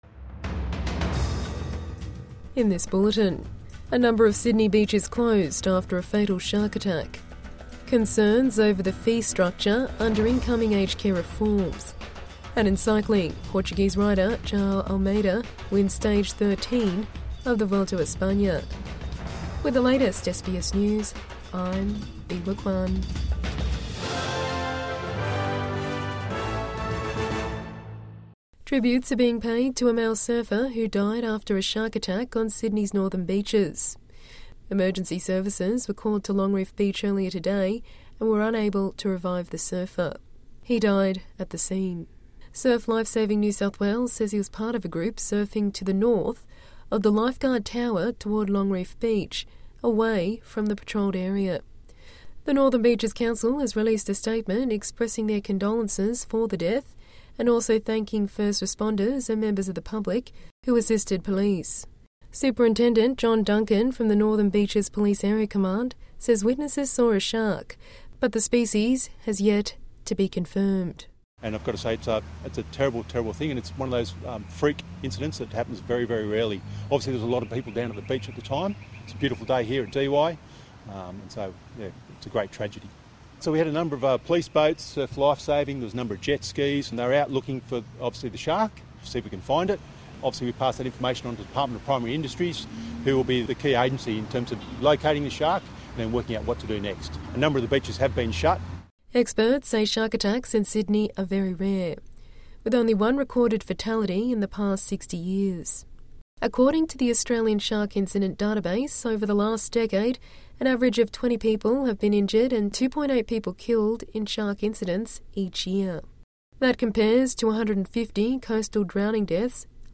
Evening News Bulletin 6 September 2025